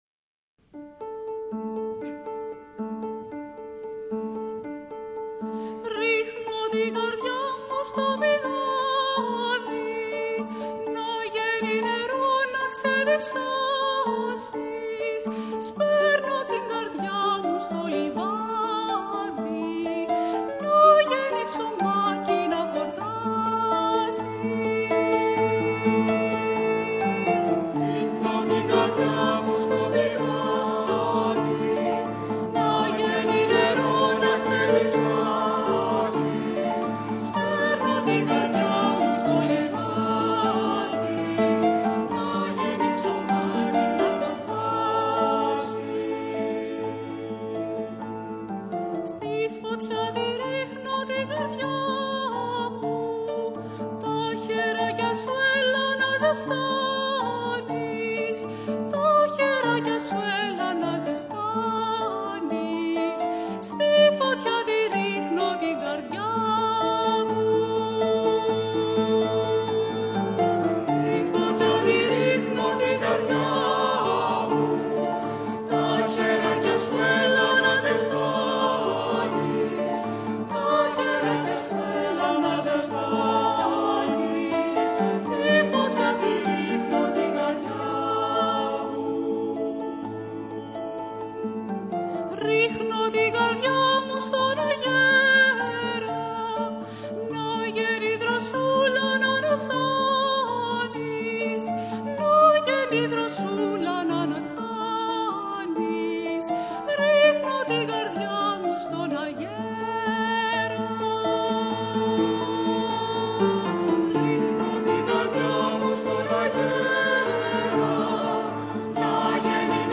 Greek modern